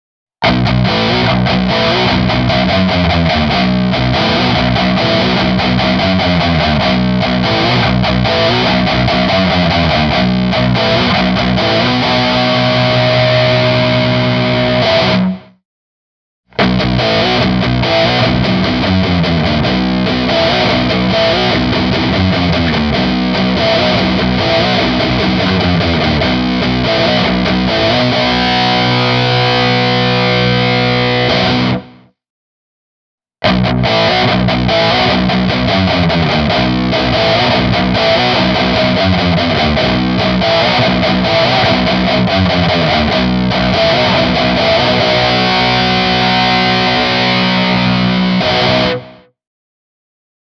LTD SC-337 on oikea valinta, kun meno muuttuu rankaksi!
Aktiivimikrofonien häiriövapaus ja niiden teho, sekä bassoalueen potku ja keskialueen avoimuus ovat lajityypin suuria valtteja, eivätkä LTD:n ESP Designed -mikrofonit jätä soittajaa pulaan.
Tällainen oli meno nykyaikaisen high gain -kanavan läpi soitettuna: